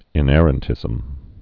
(ĭn-ĕrən-tĭzəm)